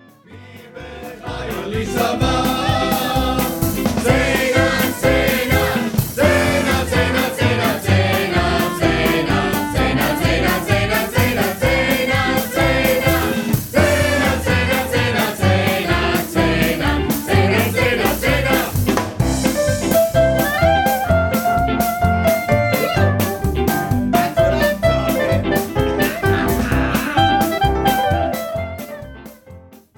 soaring harmonies and soothing melodies